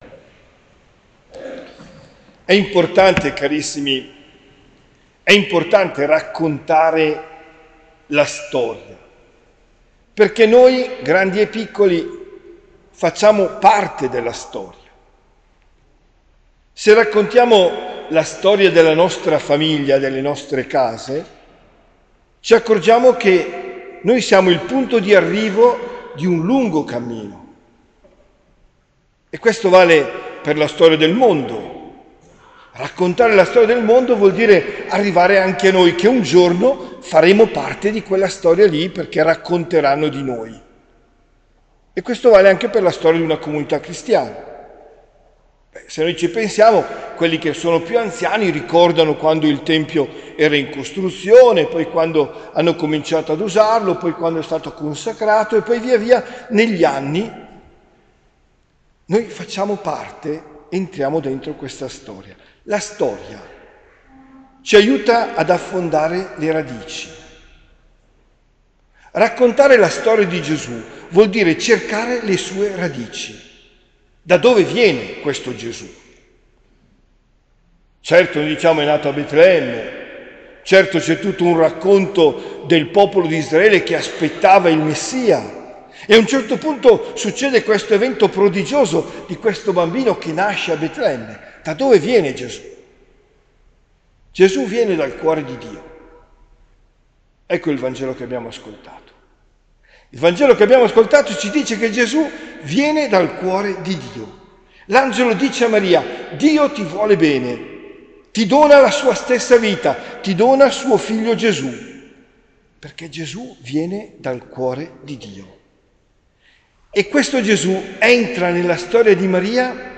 OMELIA DEL 24 DICEMBRE 2023